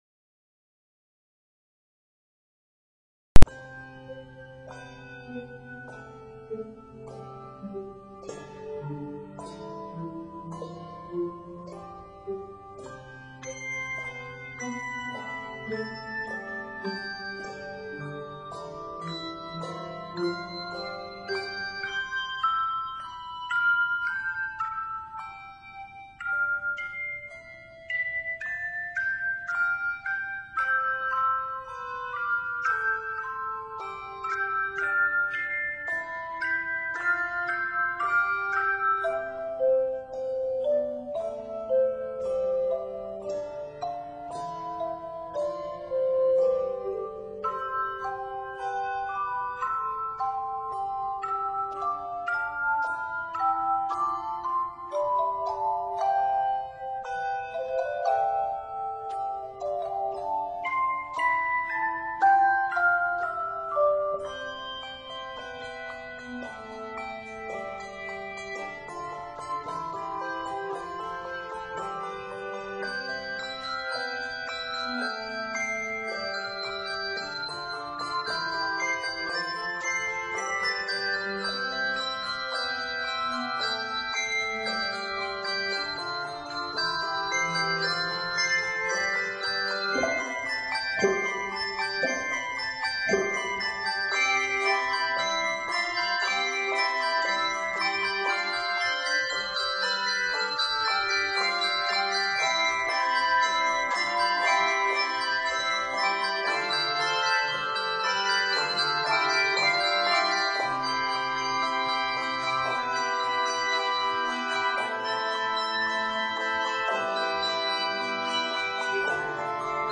Octaves: 3-6 Level